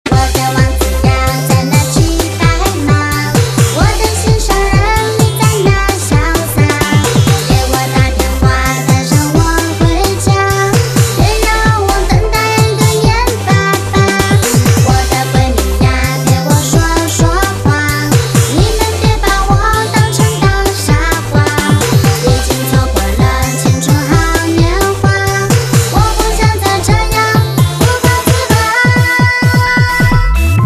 华语歌曲
可爱